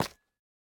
latest / assets / minecraft / sounds / block / calcite / step4.ogg
step4.ogg